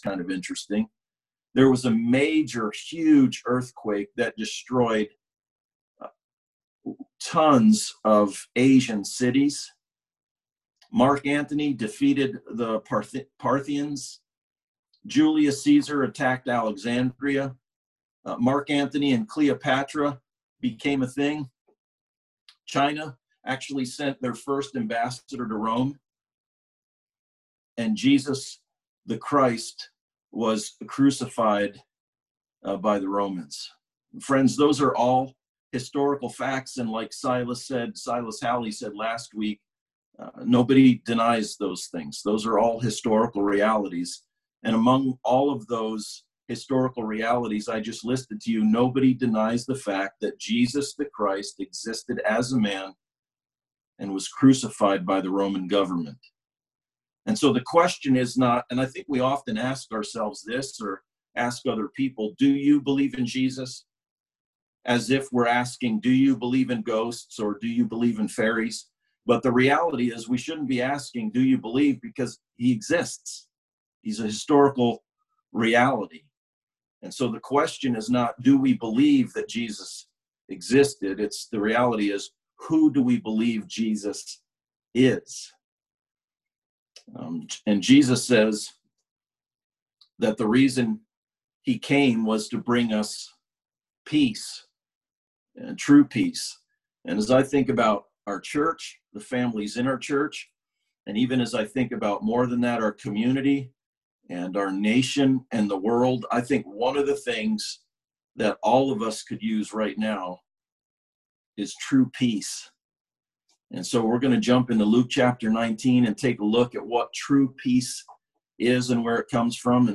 Palm Sunday Zoom sermon
Passage: Luke 19:28-44 Service Type: Sunday Service